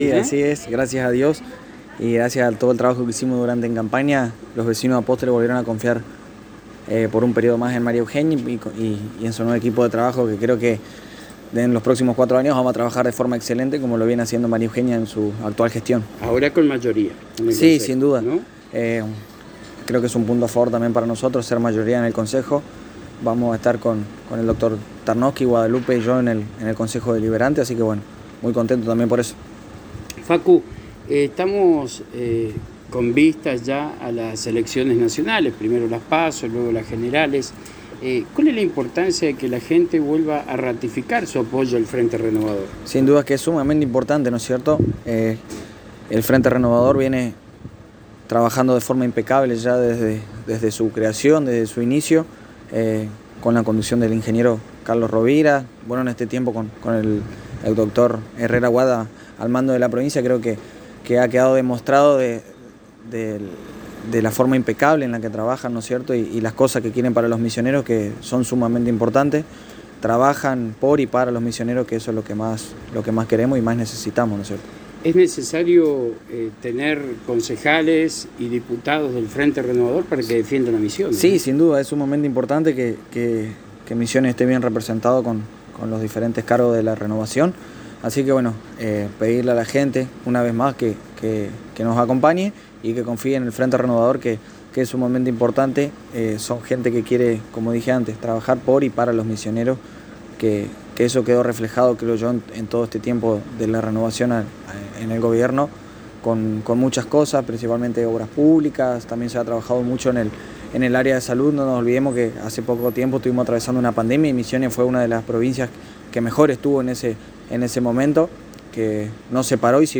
El Concejal electo de Apóstoles, Facundo Bustos, del Sub Lema Renovador Sigamos Creciendo, en diálogo exclusivo con la ANG manifestó su agradecimiento al pueblo de Apóstoles por el apoyo recibido por parte de la ciudadanía apostoleña, quien además de reelegir por cuatro años más a la actual mandataria, le otorgó la mayoría automática en el Honorable Concejo Deliberante.